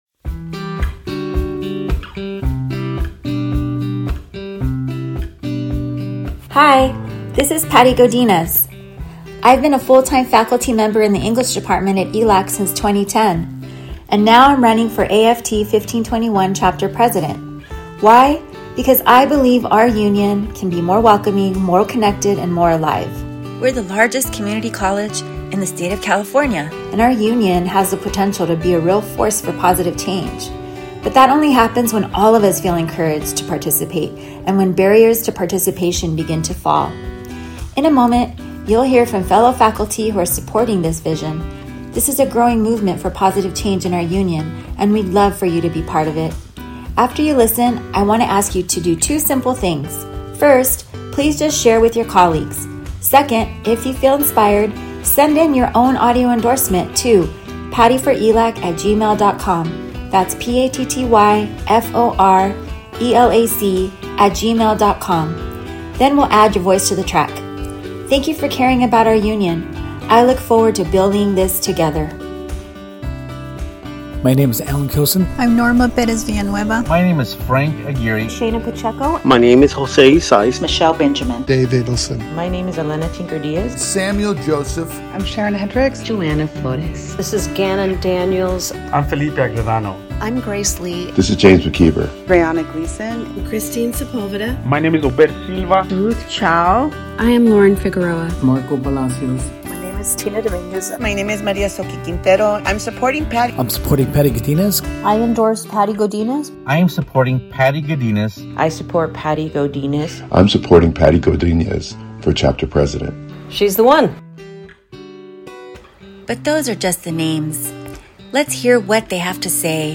Listen to what colleagues who support this vision are saying.